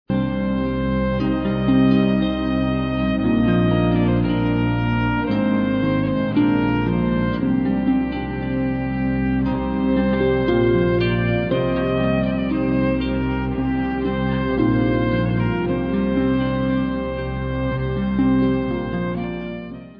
Celtic-worldbeat-folk-pop//special box